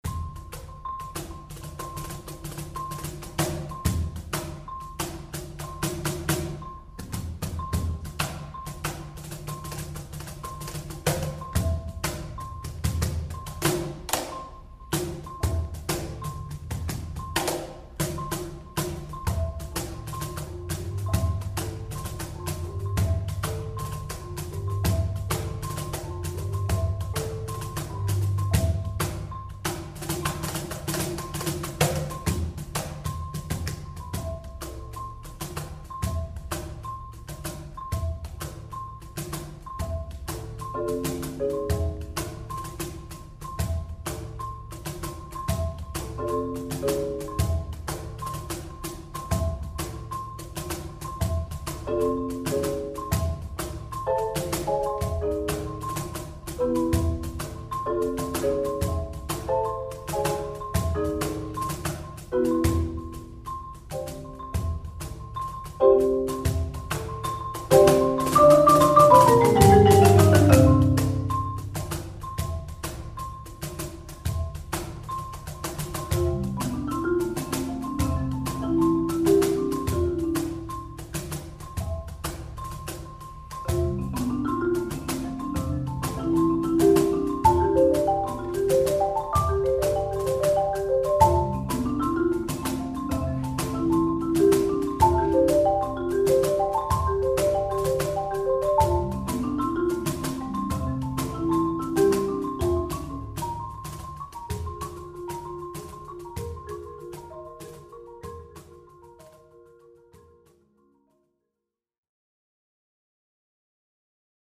Voicing: Mallet Ensemble